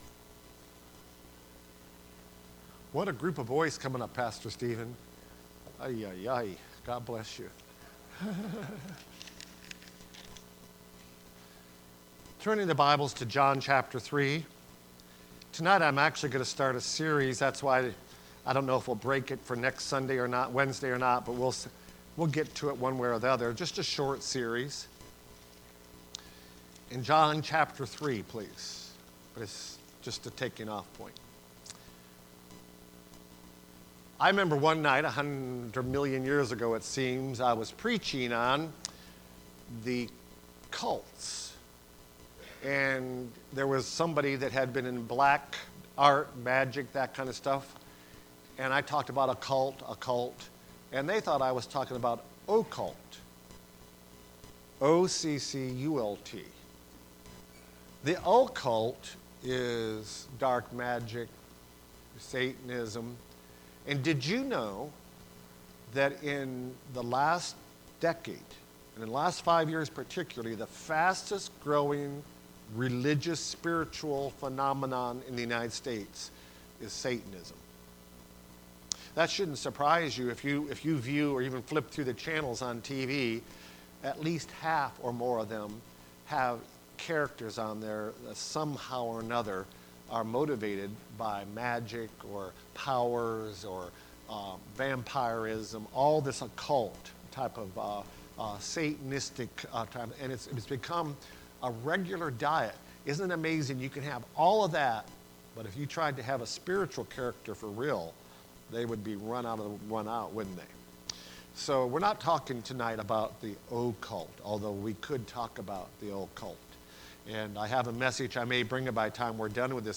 Service Type: Wednesday Prayer Service Preacher